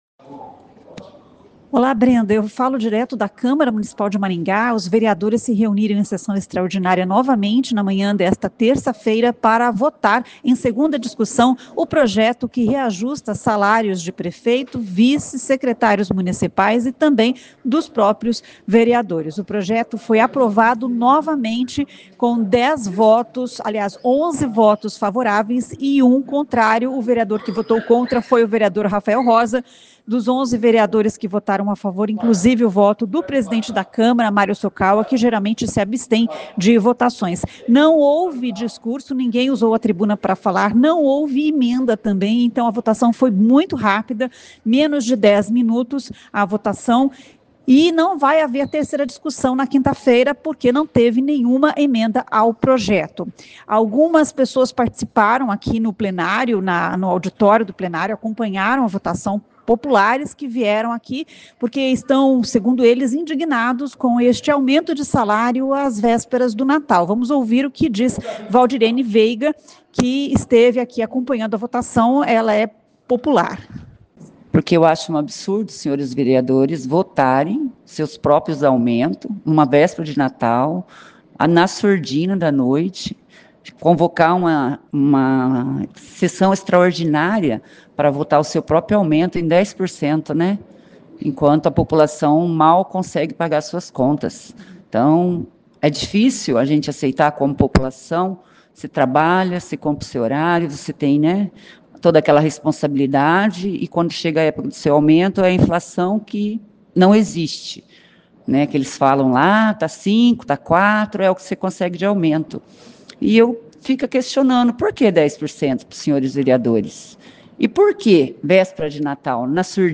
Os vereadores de Maringá aprovaram, sem discursos e sem emendas, o projeto que reajusta os salários de prefeito, vice, secretários e vereadores, a partir de 2025. O projeto foi aprovado em segunda discussão durante uma nova sessão extraordinária realizada na manhã desta terça-feira (24).